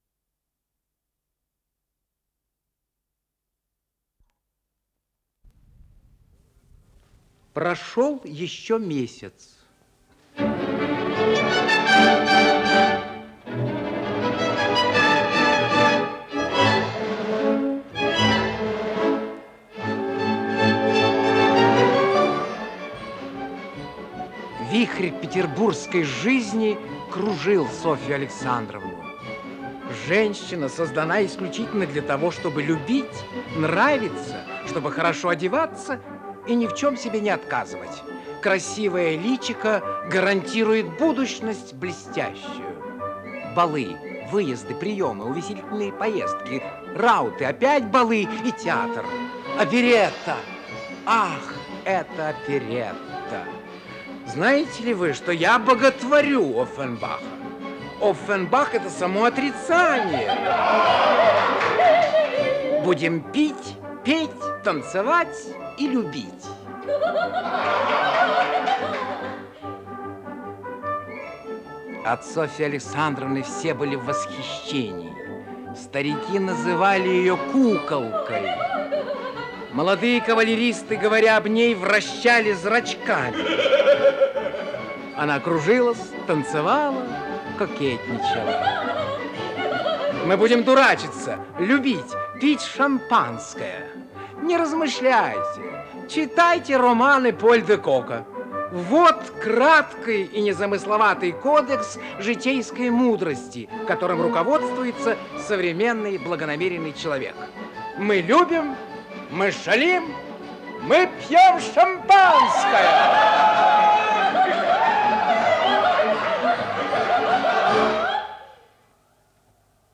Исполнитель: Артисты государственного ленинградского нового театра